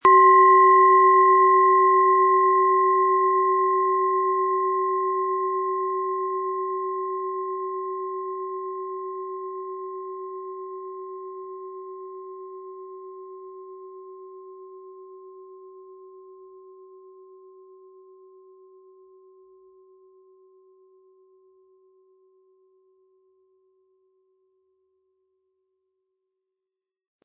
Biorhythmus Geist Klangschale Ø 10,5 cm im Sound-Spirit Shop | Seit 1993
Planetenton 1
Von Hand getriebene Schale mit dem Planetenton Biorhythmus Geist.
MaterialBronze